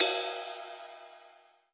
ride2.wav